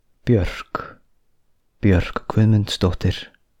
Björk Guðmundsdóttir OTF (/bjɜːrk/ BYURK, Icelandic: [pjœr̥k ˈkvʏðmʏntsˌtouhtɪr̥]